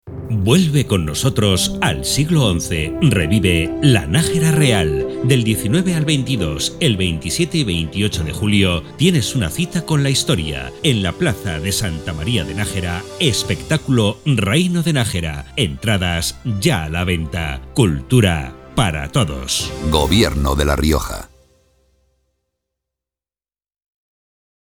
Elementos de Campaña Cuña radiofónica Cuña de 20".